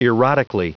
Prononciation du mot erotically en anglais (fichier audio)
Prononciation du mot : erotically